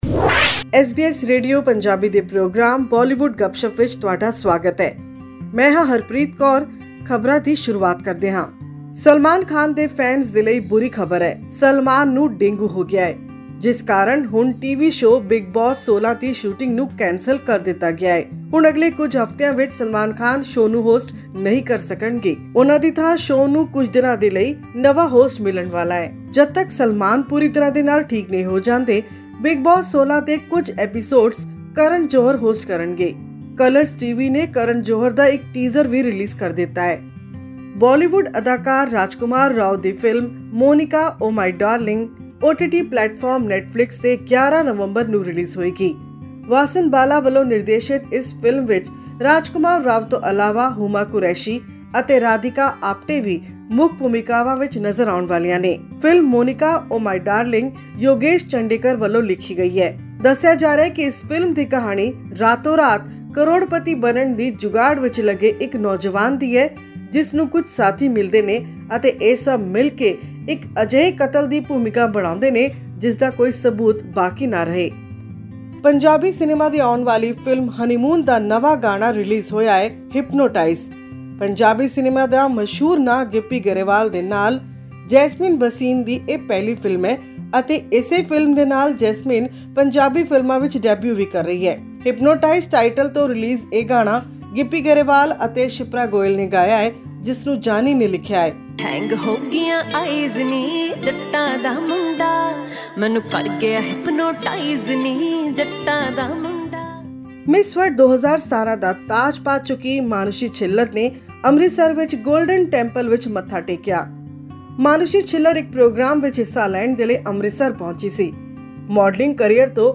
Gippy Grewal has teamed up with Jasmin Bhasin in a newly released Punjabi movie, Honeymoon. Unaware of what a honeymoon is, a family joins a newlywed couple on a crazy roller-coaster ride to create chaos, humour and rib-tickling comedy. To know more about the upcoming movies and songs, listen to our weekly news bulletin from Bollywood.